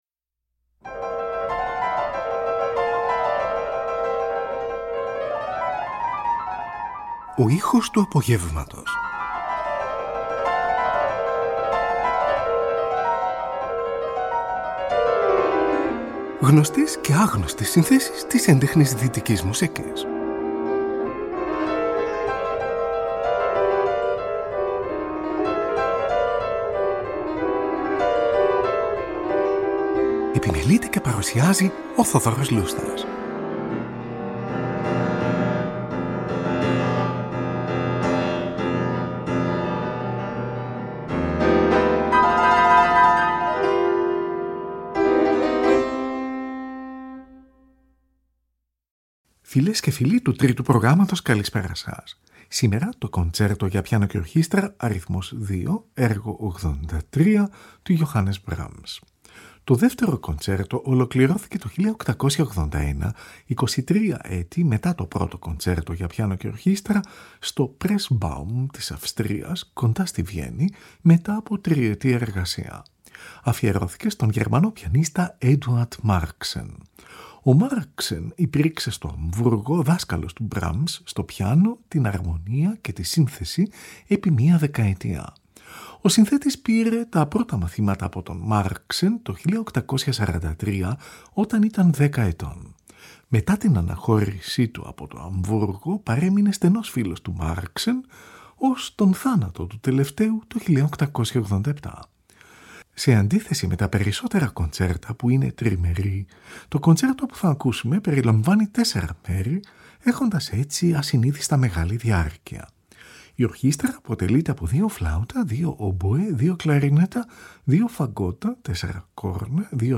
Στη σημερινή εκπομπή ακούγεται το έργο του Johannes Brahms : Κοντσέρτο για Πιάνο και Ορχήστρα Αρ. 2, έργο 83. Σολίστ , ο Adrian Aeschbacher . Τη Φιλαρμονική του Βερολίνου διευθύνει ο Wilhelm Furtwängler , από ζωντανή ηχογράφηση μεταξύ 12 και 15 Δεκεμβρίου 1943 , κατά τη διάρκεια του Δευτέρου Παγκοσμίου Πολέμου .